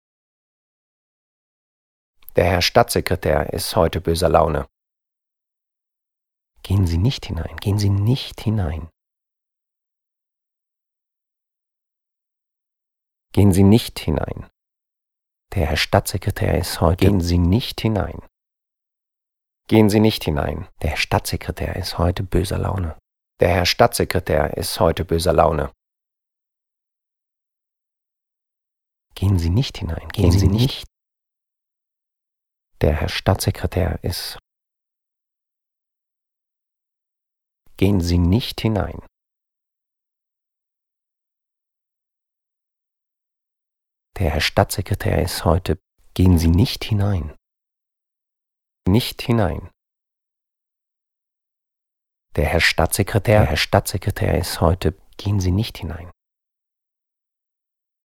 Multi-source Sound Installation
Multi-source Sound (loops)
The resulting three short recordings were installed in the corridor of the Delphi Theatre and ran as loops for the duration of the performance.